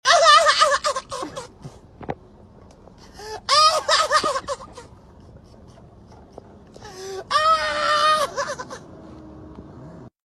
Lowkey sounds like Jungkook's laugh